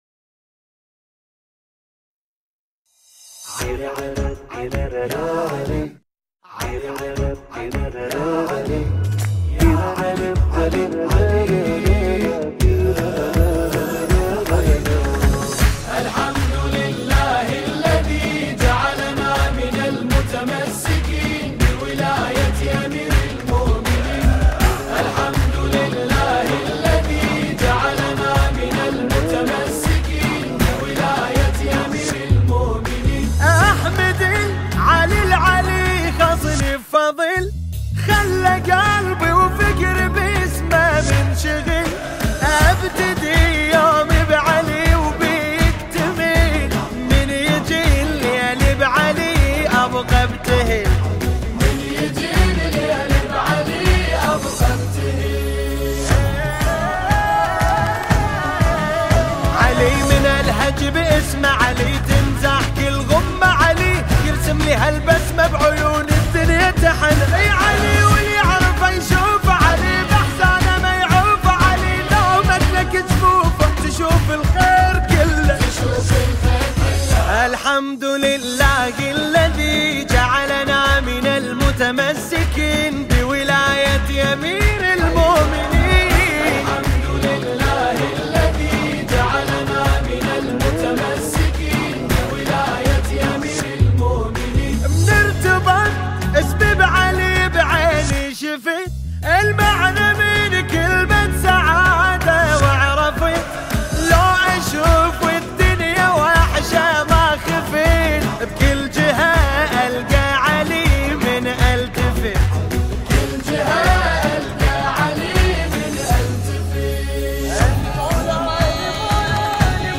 ویژه عید سعید غدیر خم